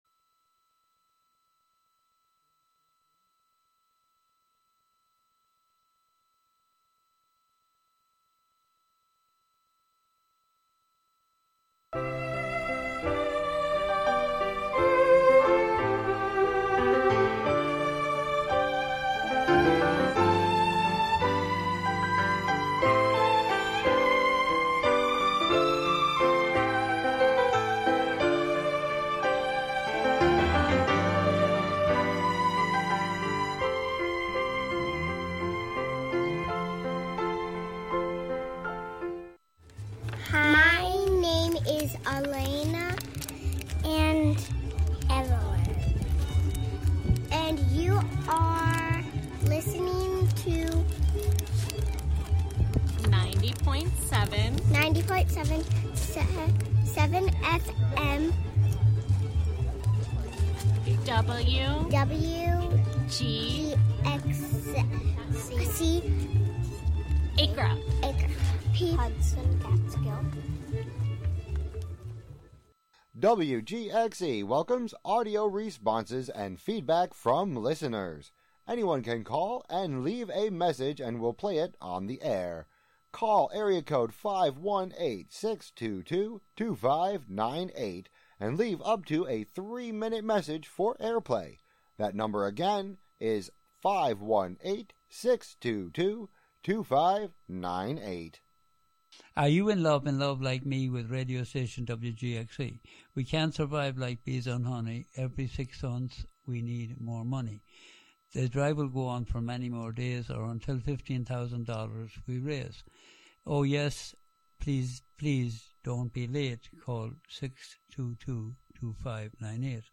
broadcast live from WGXC's Catskill studio.